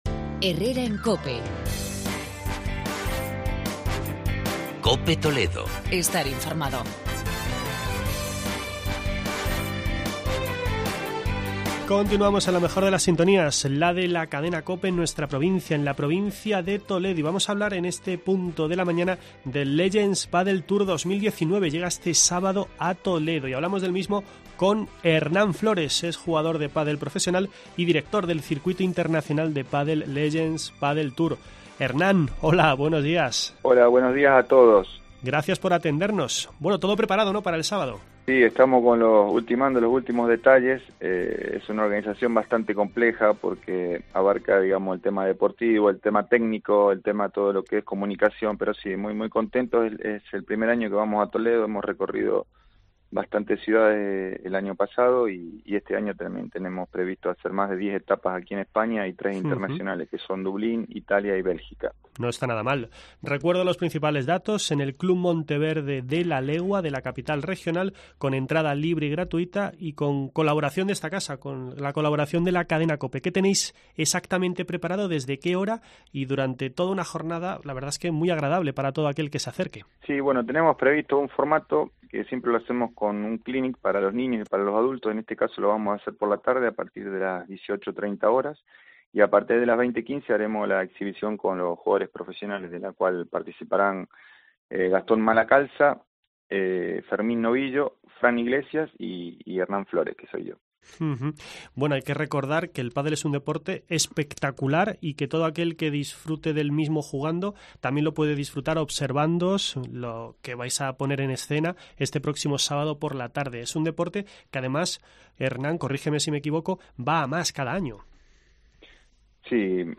AUDIO: La cita será este sábado en las pistas de pádel del Club Monteverde. Entrevista